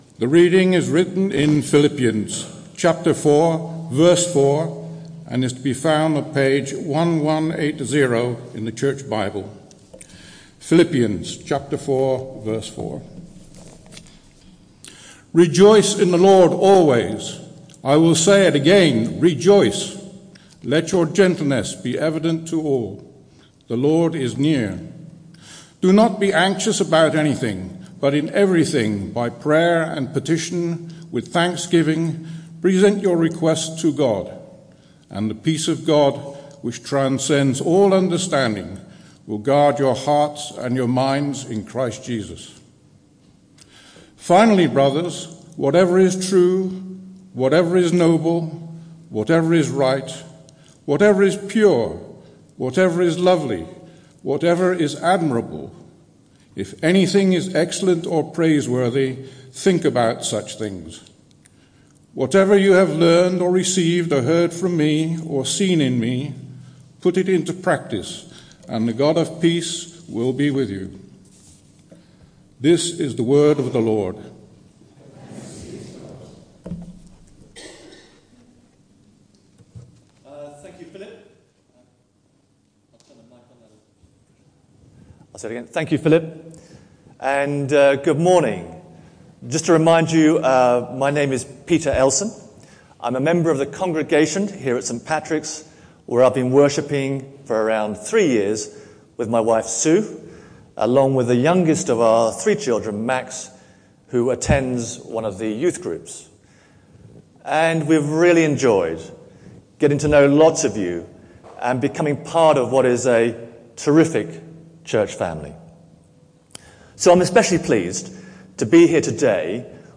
This sermon is part of a series: 10 August 2014